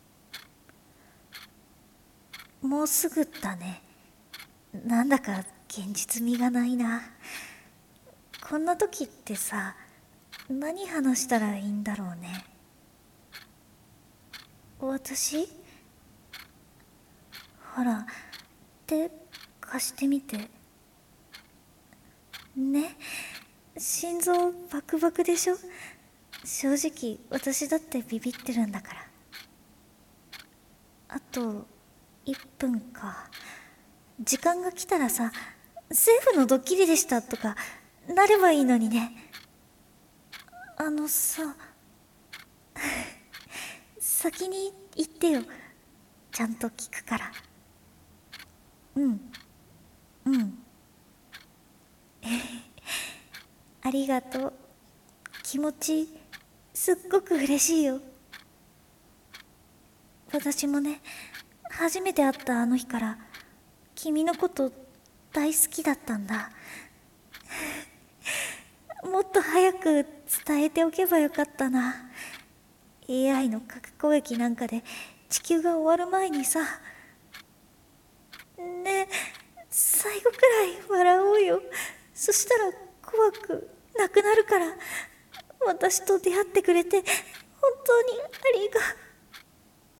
【声劇】最後の90秒【1人台本】